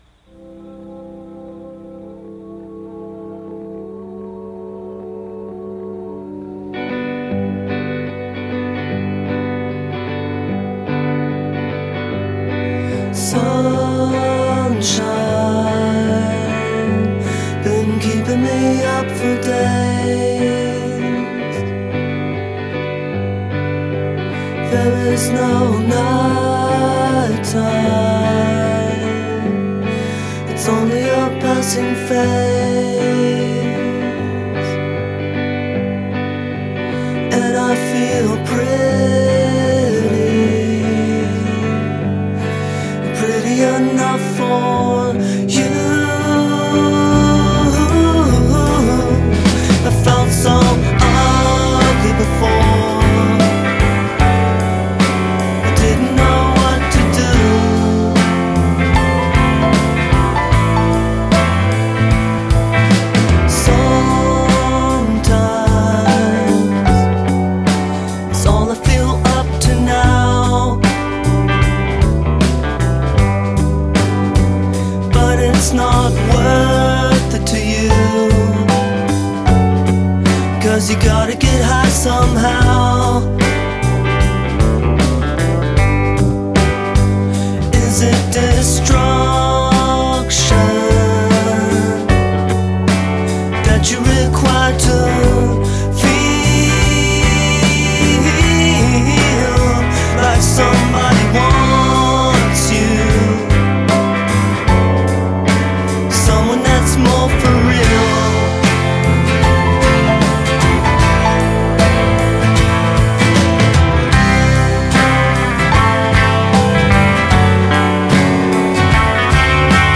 But left us some beautiful melodies.